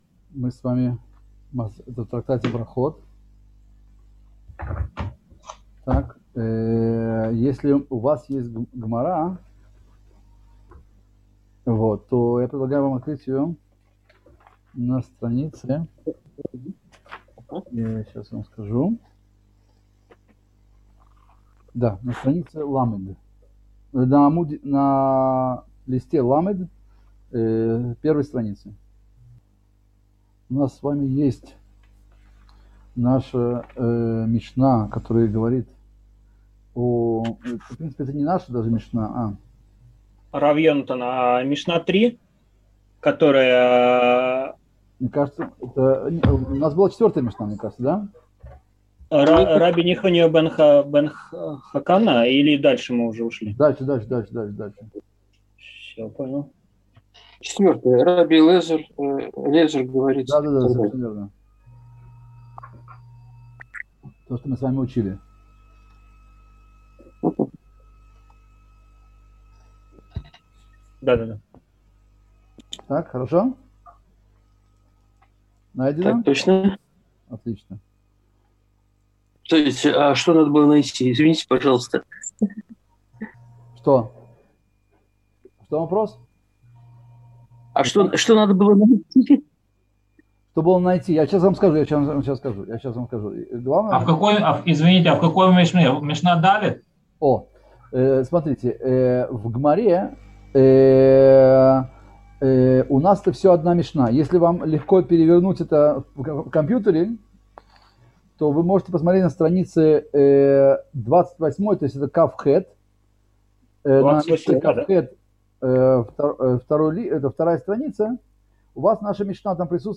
Цикл уроков по изучению мишны Брахот